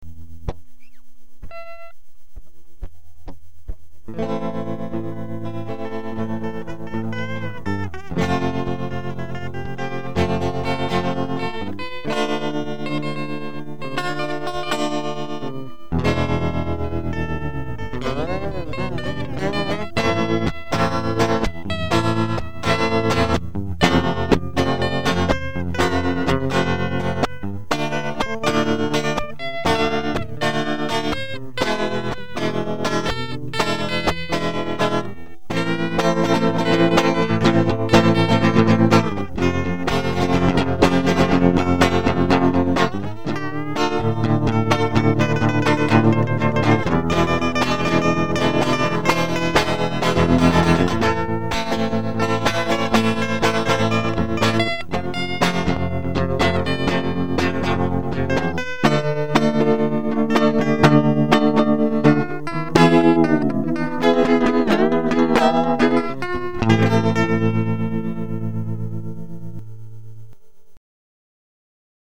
4. типа блюза...Файл mp3 весит 0.7 Mb.